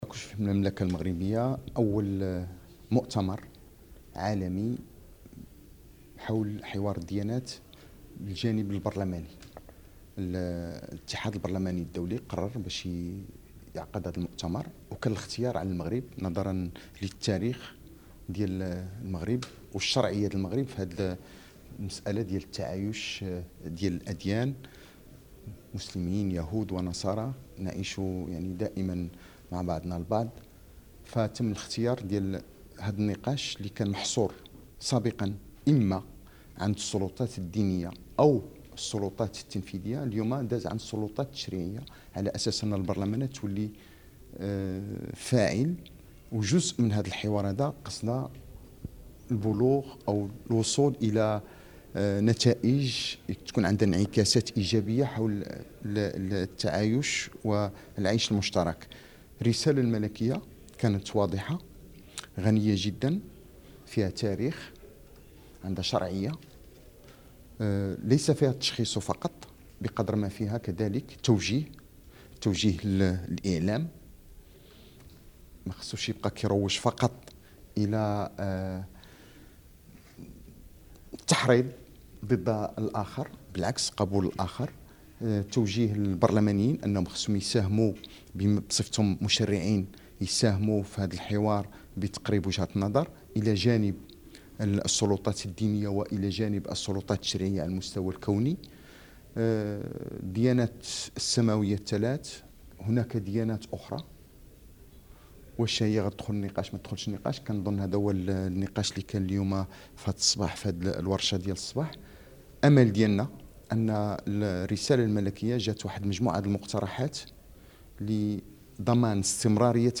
تصريح لرئيس مجلس النواب حول مؤتمر حوار الاديان
السيد راشيد الطالبي العلمي بمناسبة أشغال المؤتمر البرلماني حول الحوار بين الأديان، الذي ينظمه، تحت الرعاية السامية لصاحب الجلالة الملك، الاتحاد البرلماني الدولي والبرلمان المغربي بمدينة مراكش من 13 إلى 15 يونيو الجاري.
interview-president1-.mp3